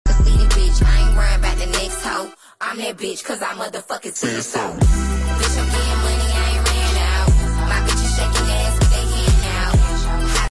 Ab twister machine shaking. sound effects free download